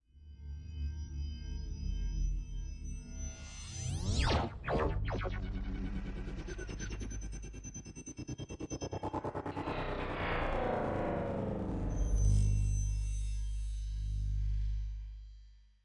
描述：由各种合成器制成的变压器的声音